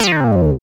Index of /90_sSampleCDs/Roland - Rhythm Section/BS _Synth Bass 1/BS _Synth Bs FX